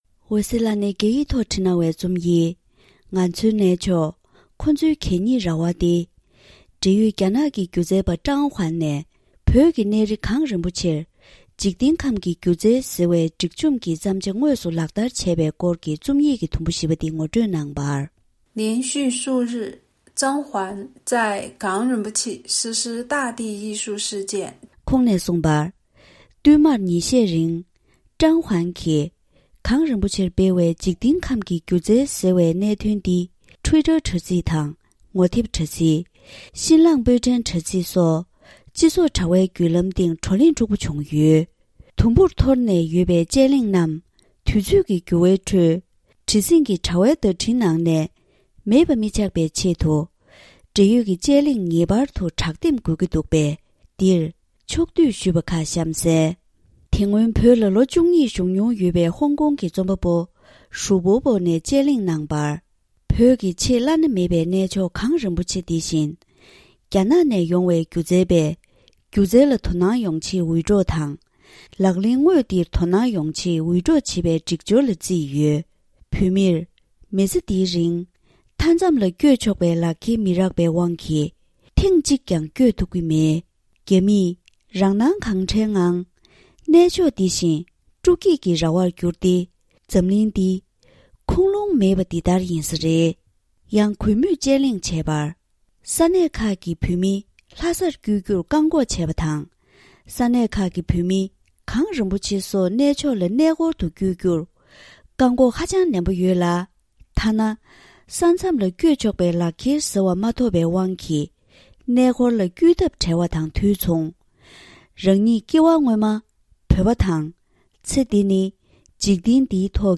ང་ཚོའི་གནས་མཆོག་དང་ཁོང་ཚོའི་གད་སྙིགས་རྭ་བ། དུམ་བུ་གསུམ་པ། སྒྲ་ལྡན་གསར་འགྱུར། སྒྲ་ཕབ་ལེན།